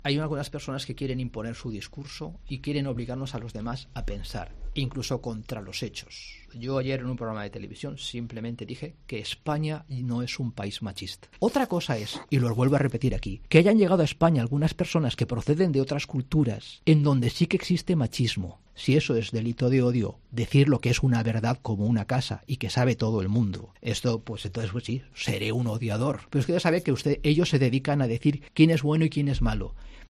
Esta mañana, el portavoz de Vox y segundo teniente de alcalde en el Ayuntamiento de València, Juanma Badenas, en una entrevista en COPE Valencia, insistía en lo que asegura que